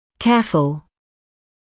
[‘keəfl][‘кэафл]внимательный, тщательный; осторожный, осмотрительный; заботливый